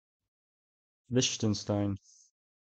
Liechtenstein (/ˈlɪktənstn/ , LIK-tən-styne;[13] German: [ˈlɪçtn̩ʃtaɪn]